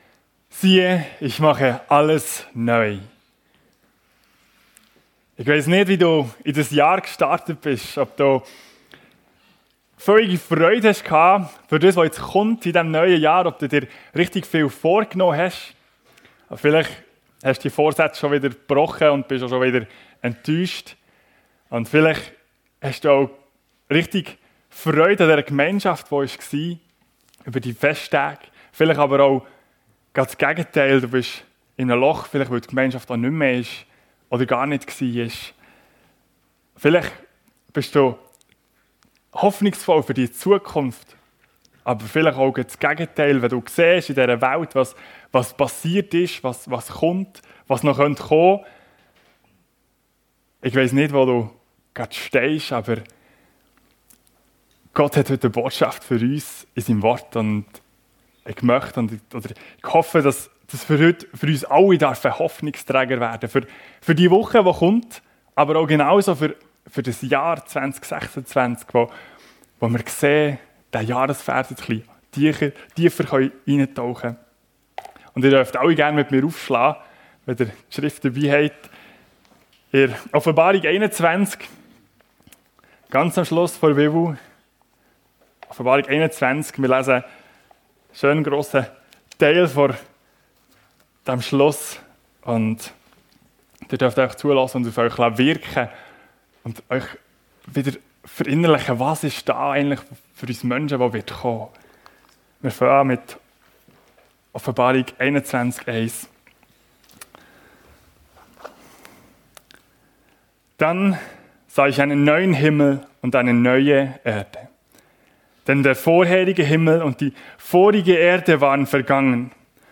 FEG Sumiswald - Predigten Podcast